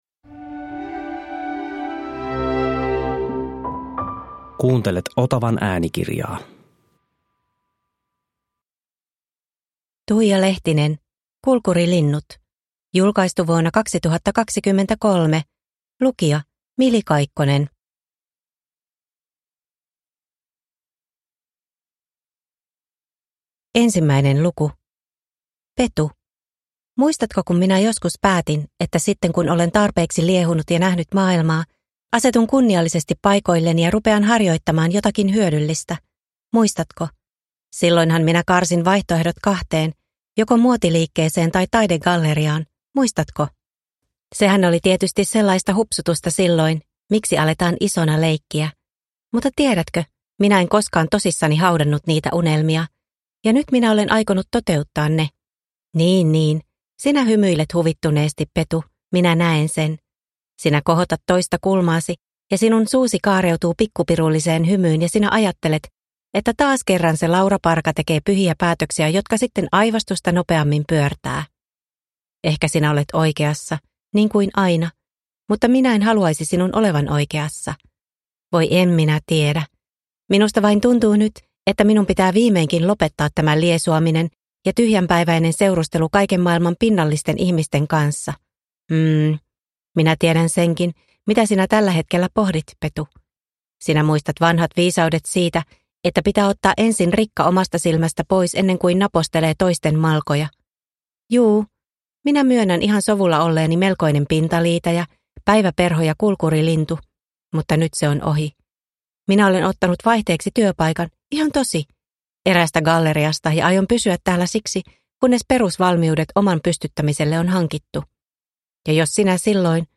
Kulkurilinnut – Ljudbok – Laddas ner